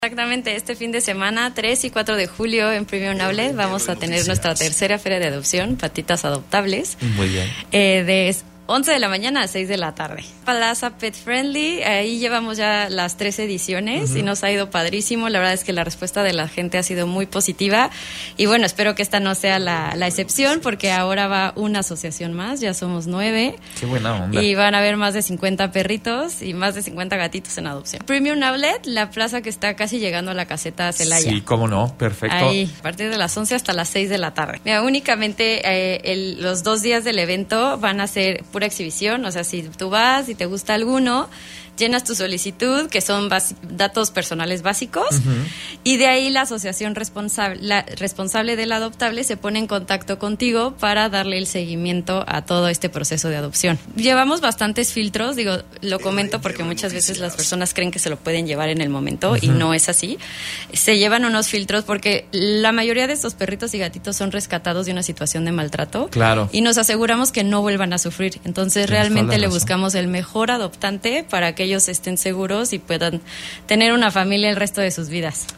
La Regidora del Municipio de Corregidora, Lennyz Meléndez, nos habla sobre la feria de adopciones Patitas Adoptables